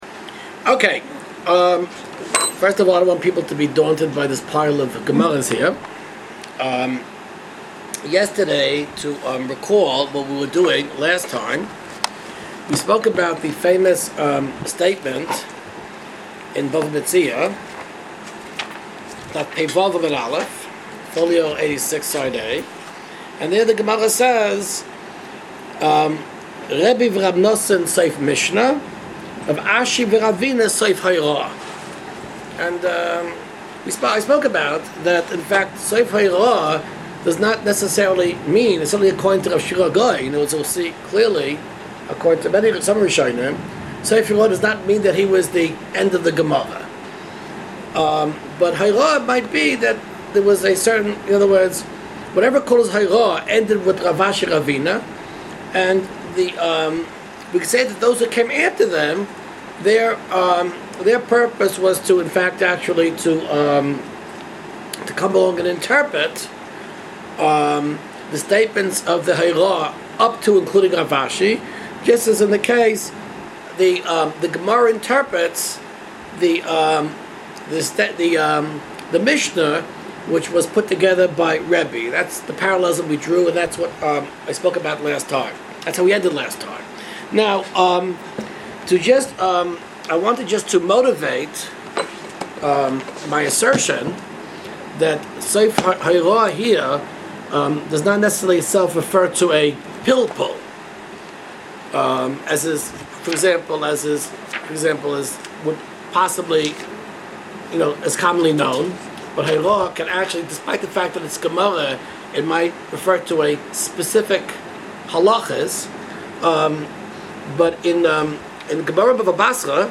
This difference of opinion also leads to an argument about whether Gaonim or Savoraim speak in the Talmud. [flv:/talmud/talmud_02.flv 415 210] Watch the Video Shiur History and Development of the Talmud 2 Download the video shiur History and Development of the Talmud 02 Download the shiur in mp3 format